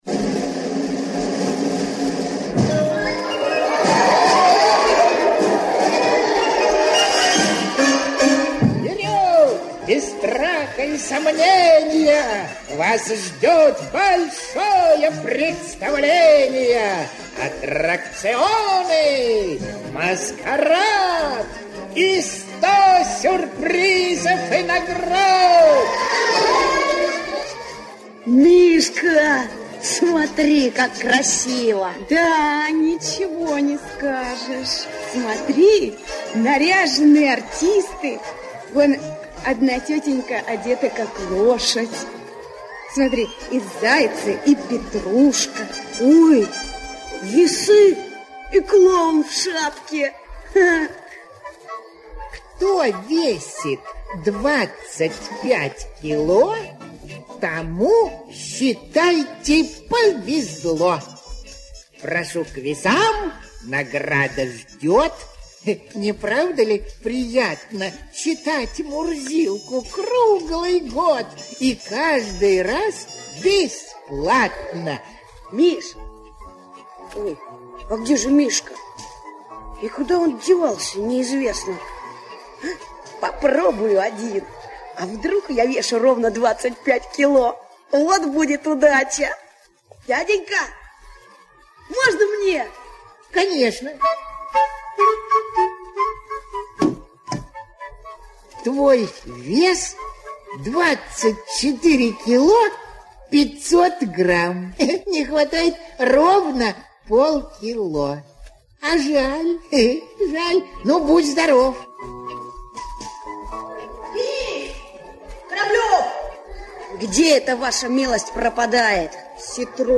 Ровно 25 кило - аудио рассказ Драгунского В.Ю. Дениска с Мишкой были на празднике, где разыгрывалась годовая подписка на журнал Мурзилка...